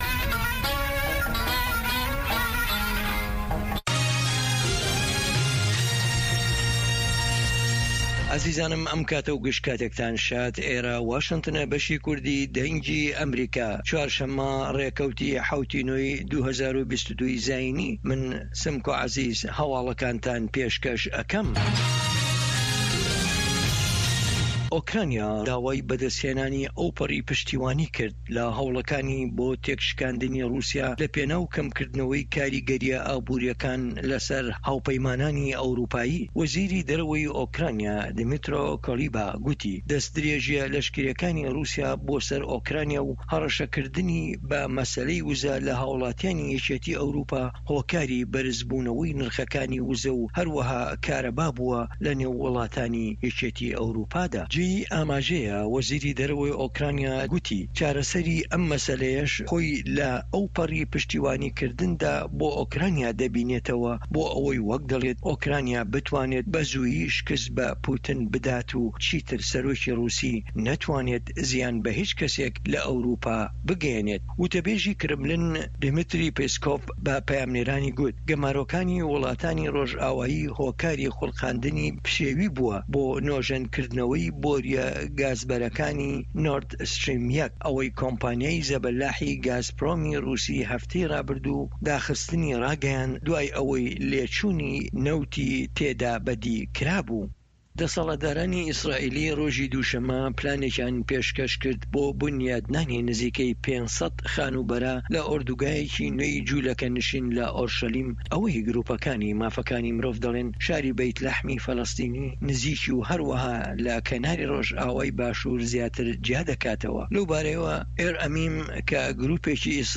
هەواڵە جیهانییەکان 2